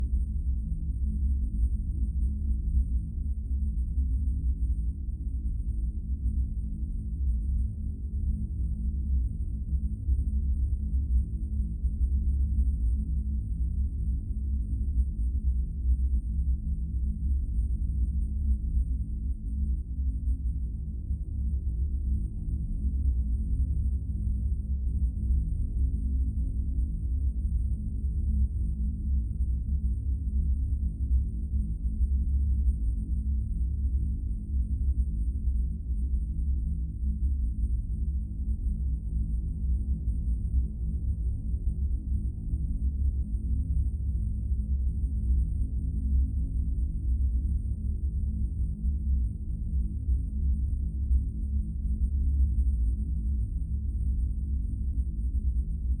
Sci-Fi Sounds / Hum and Ambience
Low Rumble Loop 1.wav